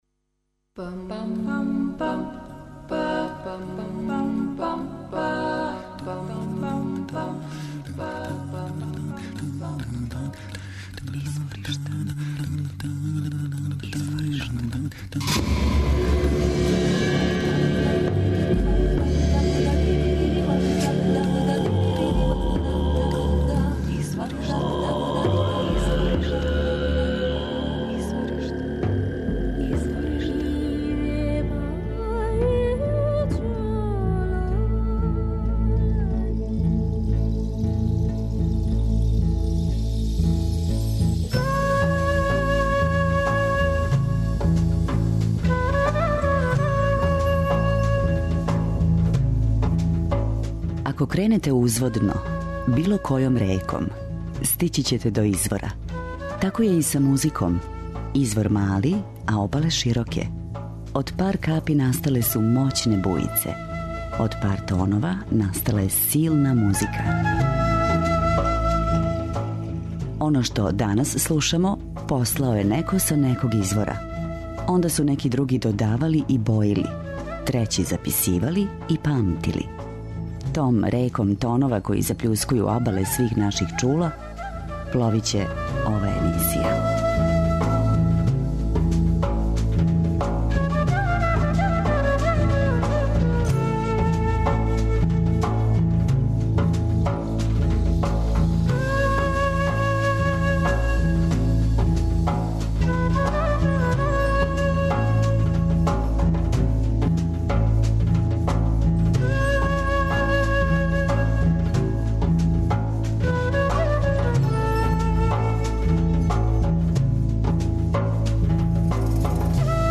Они који још увек нису отпутовали или остају преко лета у Београду моћи ће да уживају у врелим ритмовима и мелодијама из читавог света које смо одабрали у летњој шеми емисије Изворишта.
преузми : 28.46 MB Изворишта Autor: Музичка редакција Првог програма Радио Београда Музика удаљених крајева планете, модерна извођења традиционалних мелодија и песама, културна баштина најмузикалнијих народа света, врели ритмови... У две речи: World Music.